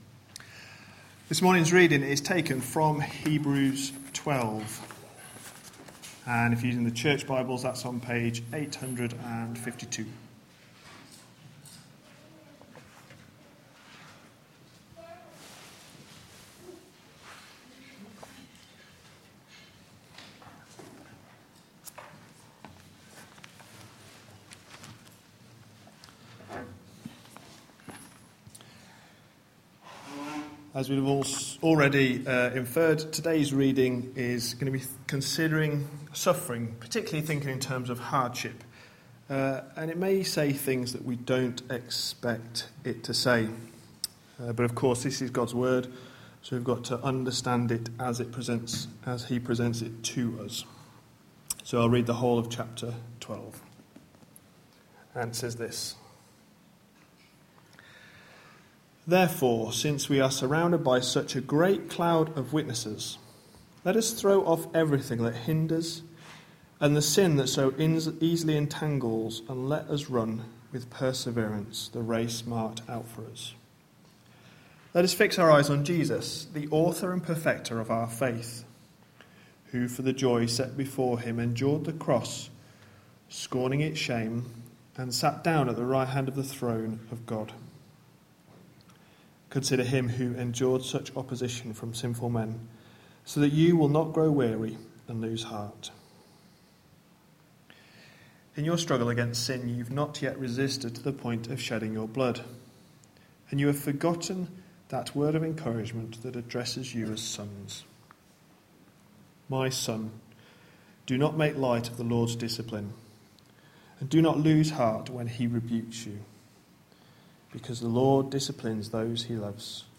A sermon preached on 8th March, 2015, as part of our Hebrews series.